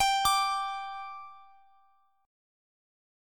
G5 Chord
Listen to G5 strummed